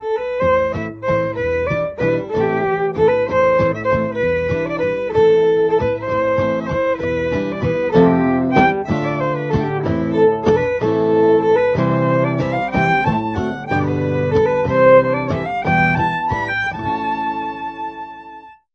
guitarist
a collection of four traditional Irish tunes